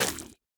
Minecraft Version Minecraft Version snapshot Latest Release | Latest Snapshot snapshot / assets / minecraft / sounds / block / roots / break6.ogg Compare With Compare With Latest Release | Latest Snapshot
break6.ogg